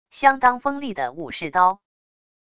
相当锋利的武士刀wav音频